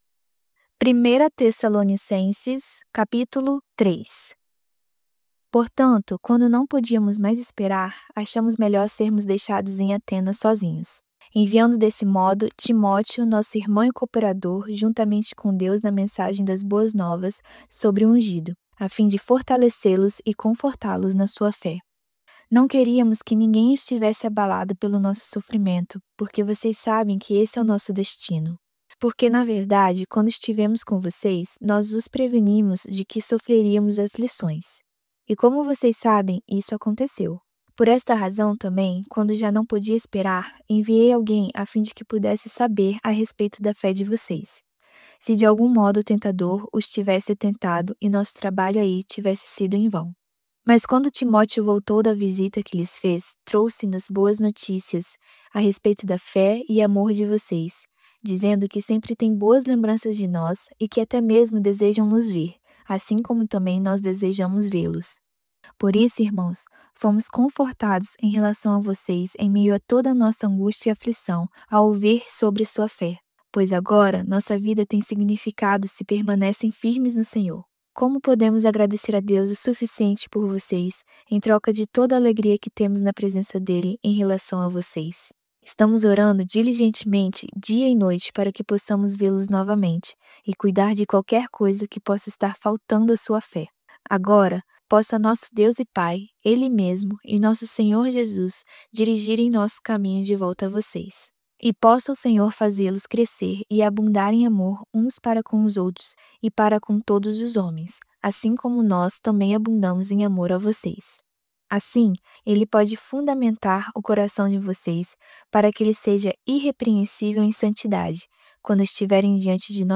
voz-audiobook-novo-testamento-vida-do-pai-primeira-tessalonicenses-capitulo-3.mp3